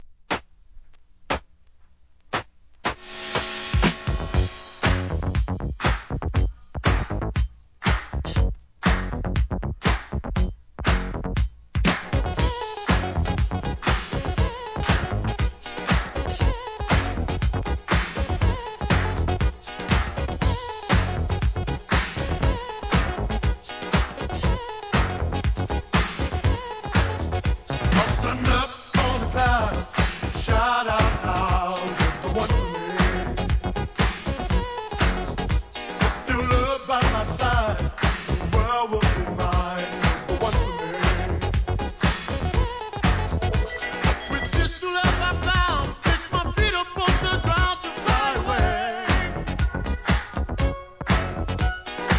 SOUL/FUNK/etc... 店舗 ただいま品切れ中です お気に入りに追加 1981年の王道ダンクラ!!!